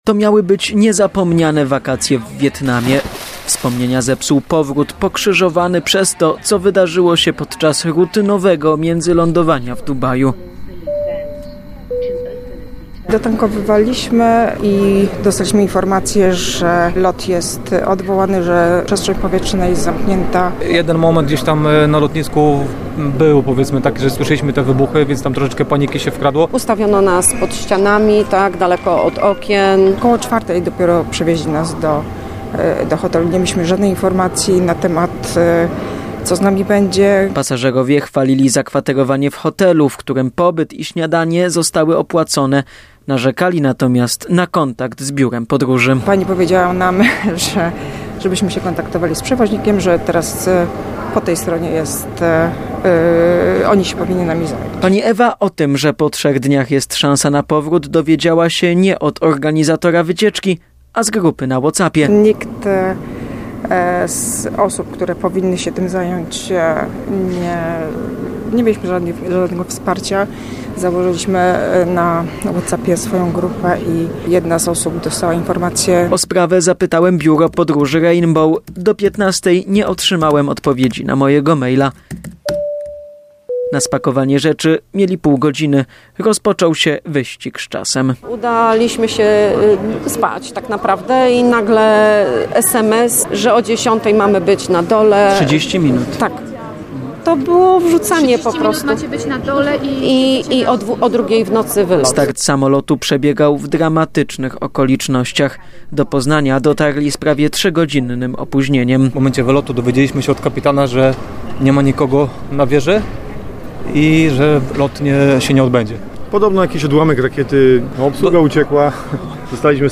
Ulga po nieprzespanych nocach – tak o swojej podróży do domu mówią pasażerowie lotu z Dubaju, który przed godziną 9.30 wylądował w Poznaniu.
- mówili podróżujący.